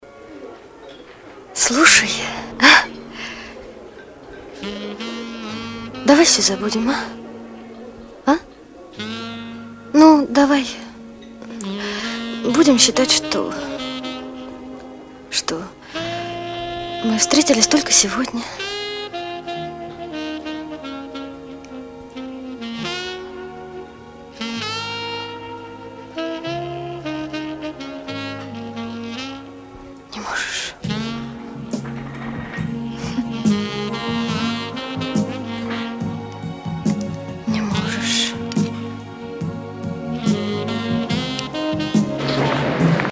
саксофонной